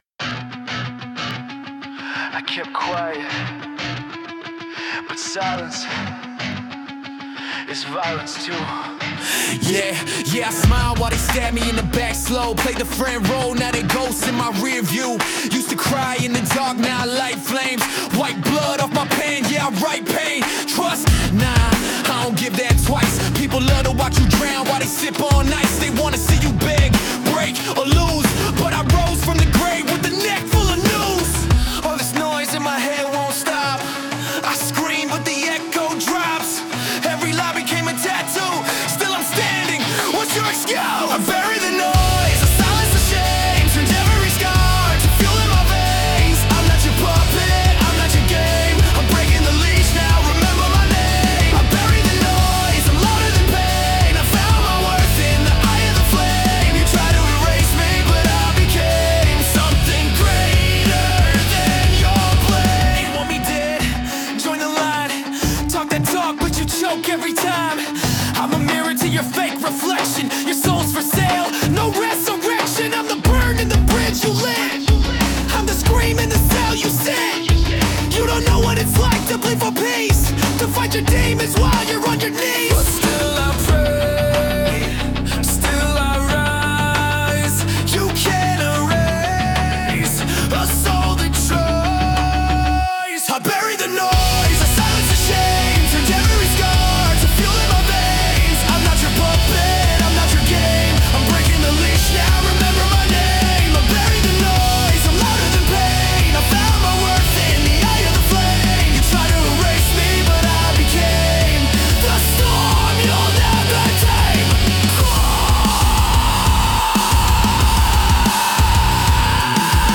Genre: Rock Mood: Awakened